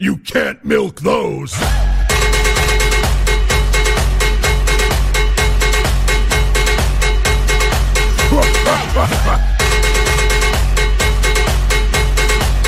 Cowbell remix